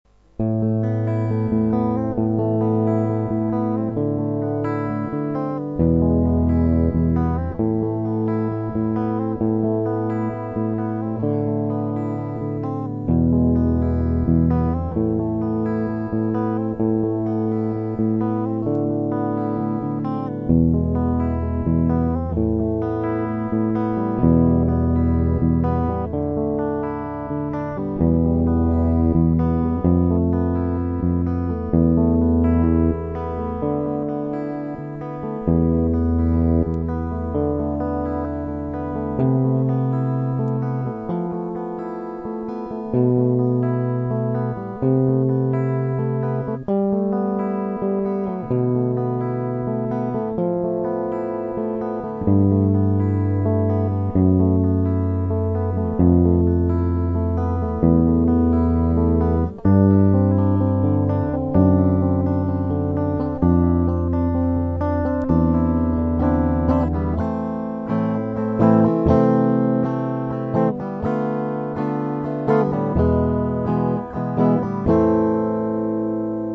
Дальше тоже перебором
mp3 - первый куплет и проигрыш до второго